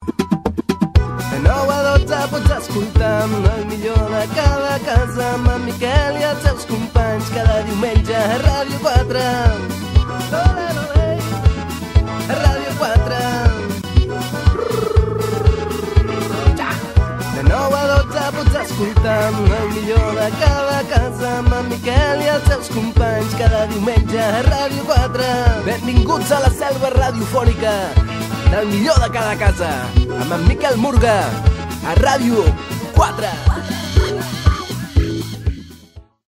Indicatiu del programa cantat a ritme de rumba catalana